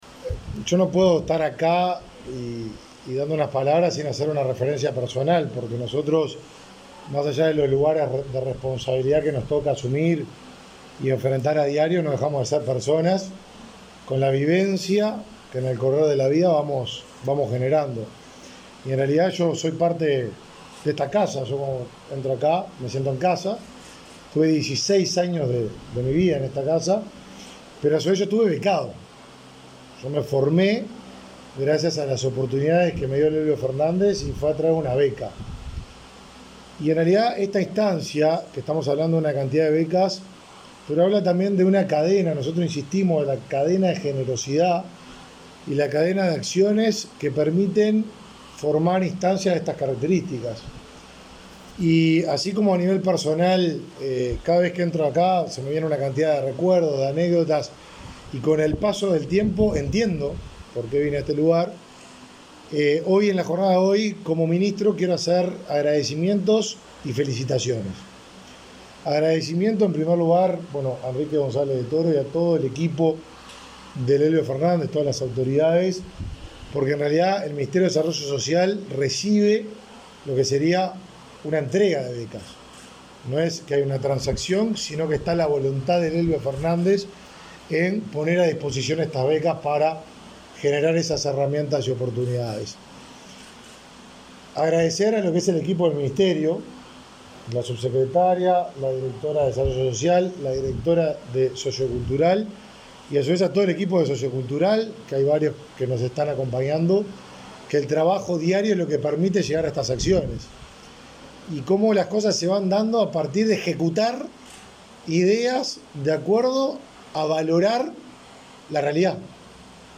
Palabras del ministro de Desarrollo Social, Martín Lema
El ministro de Desarrollo Social, Martín Lema, participó de la ceremonia de bienvenida a 20 beneficiarios de esa cartera que terminarán el